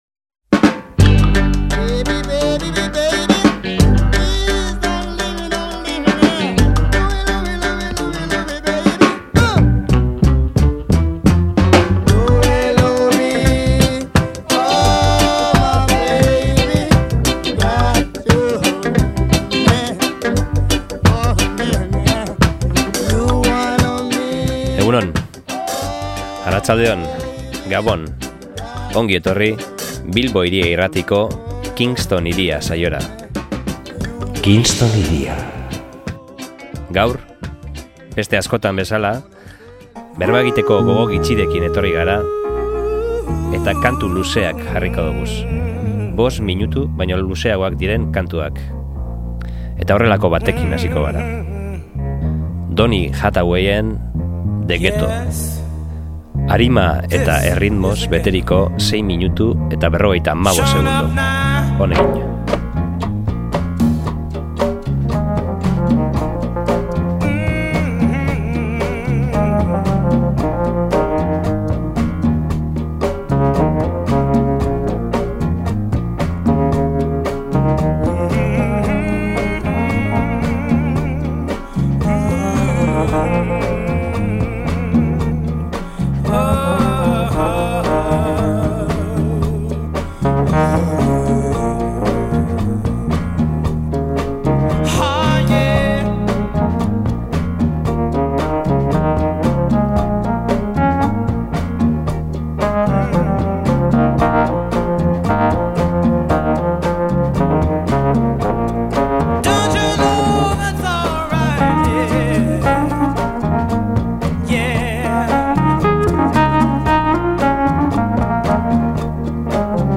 Soul, Jazz, Rap, Fonk, Elektronika, Afro-Beat…